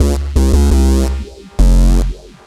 BASS25LP01-L.wav